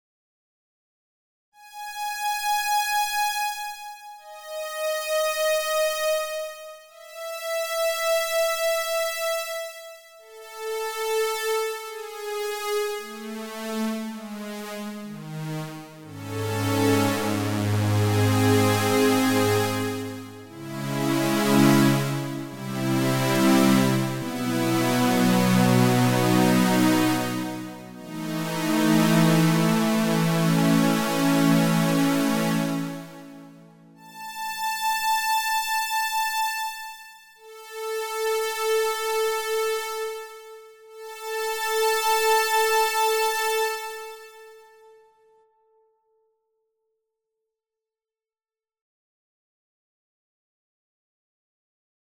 Delay och reverb pålagt i Cubase för att få stereobild och lite rymd på ljuden.
Ljudet bygger på mycket PWM från LFO1 och LFO2, vilket tyvärr ger hörbara artefakter.
M6R-CS80Strings.mp3